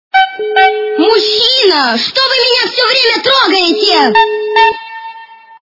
» Звуки » Смешные » Говорящий телефон - Мужчина, што Вы меня все время трогаете!
При прослушивании Говорящий телефон - Мужчина, што Вы меня все время трогаете! качество понижено и присутствуют гудки.